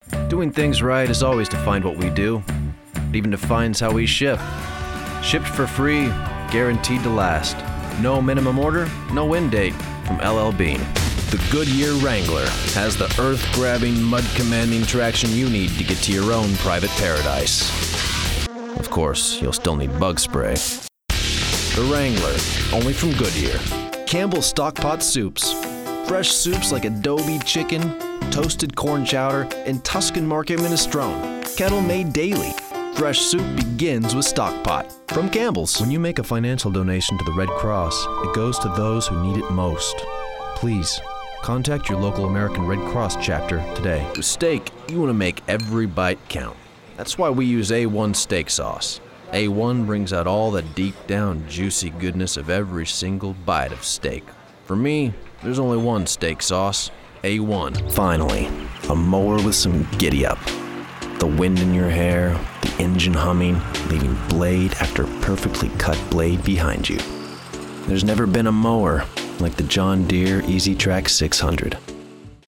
commercial : men